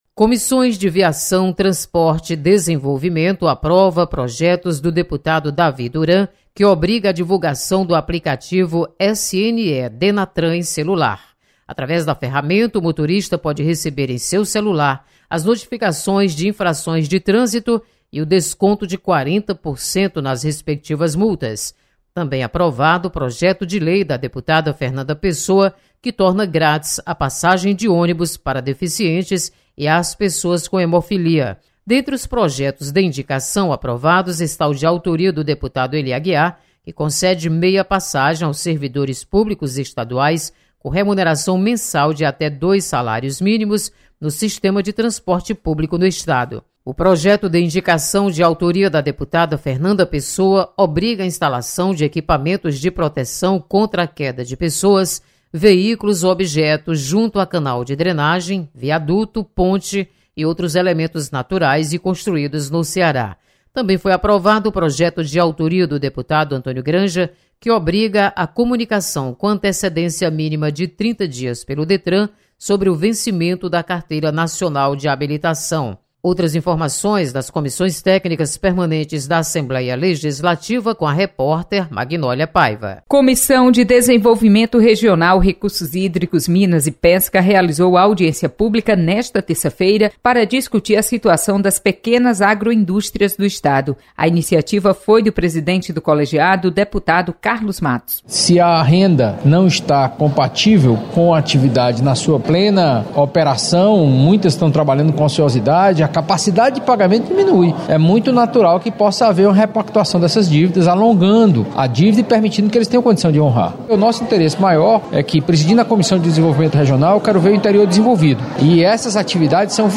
Comissões aprovam projetos e discutem sobre segurança pública. Repórter